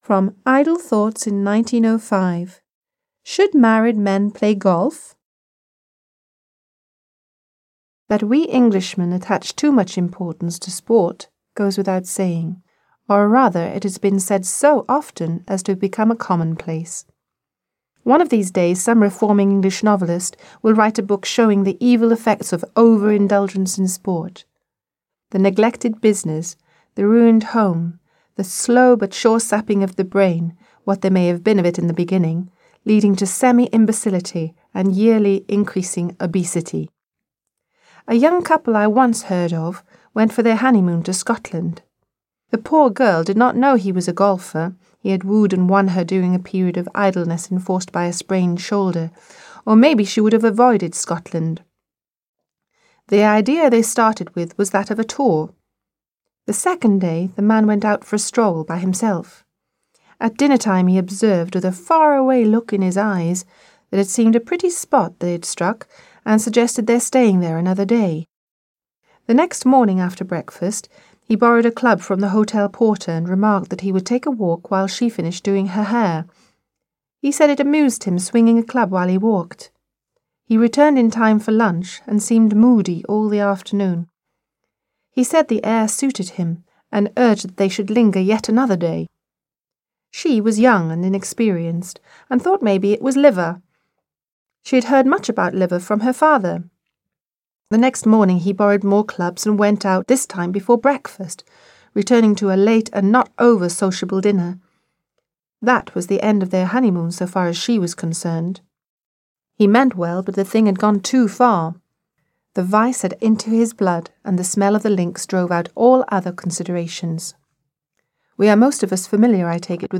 Аудиокнига Следует ли женатым мужчинам играть в гольф?